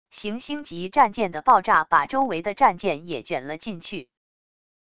行星级战舰的爆炸把周围的战舰也卷了进去wav音频